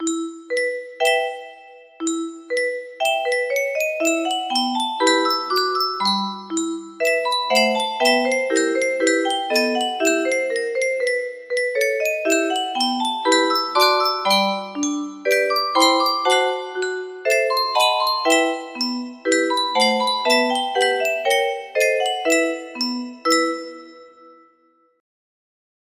Clone of Tchaikovsky - The Seasons - June music box melody